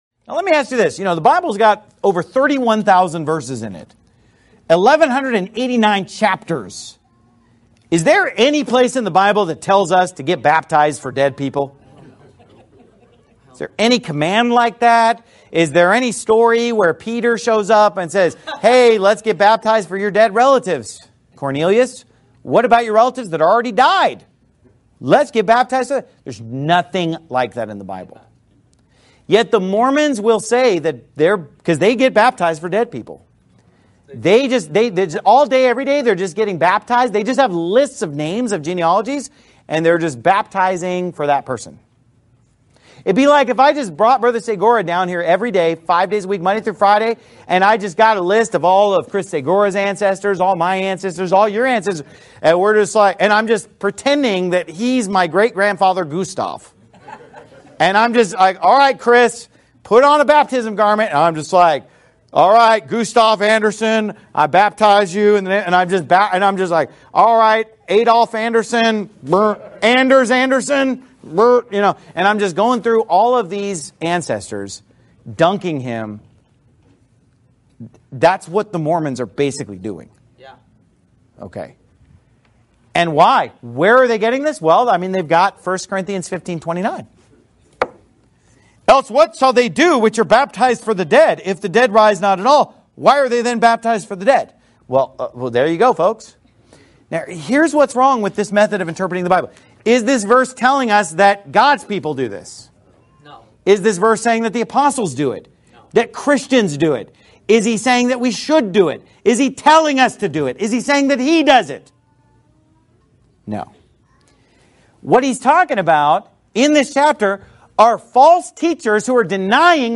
Every NIFB Sermon and More